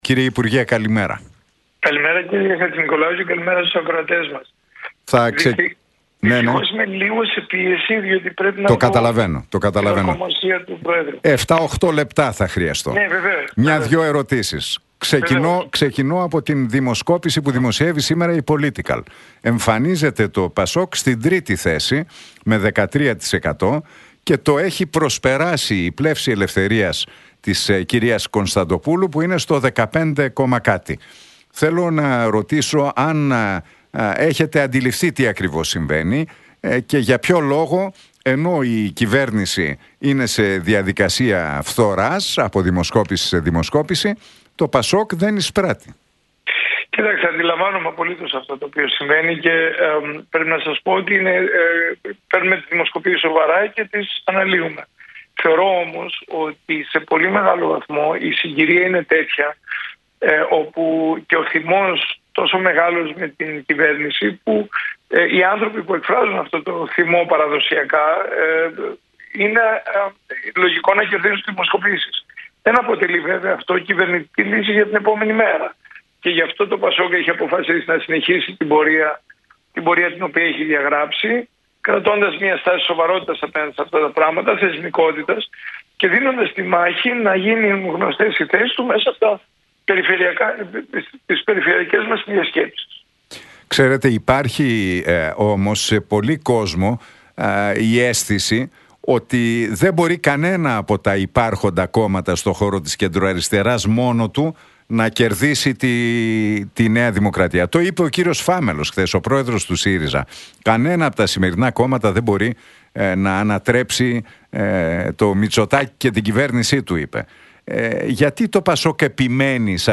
Για τις δημοσκοπήσεις, τη στρατηγική διεύρυνσης του ΠΑΣΟΚ και τον επικείμενο ανασχηματισμό της κυβέρνησης μίλησε ο κοινοβουλευτικός εκπρόσωπος του ΠΑΣΟΚ, Παύλος Γερουλάνος στον Νίκο Χατζηνικολάου από την συχνότητα του Realfm 97,8.